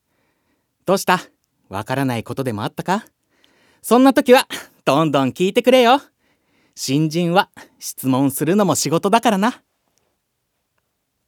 セリフ4